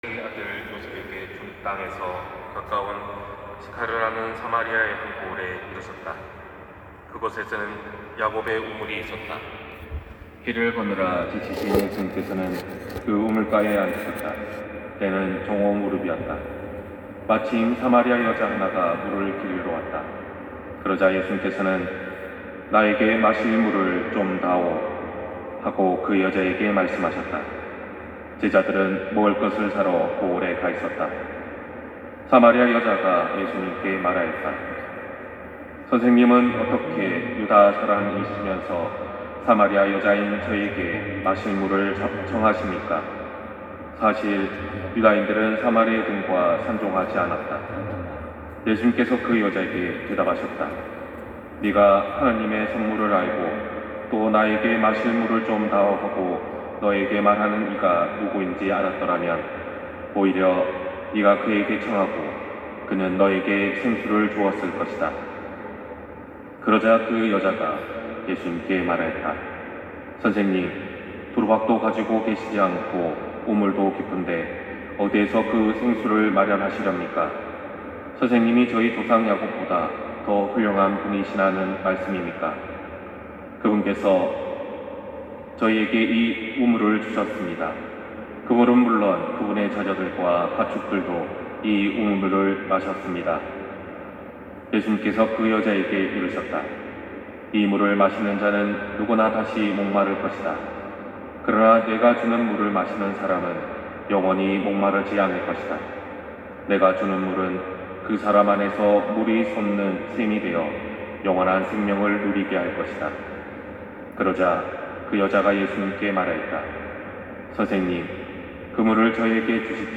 260307 신부님강론말씀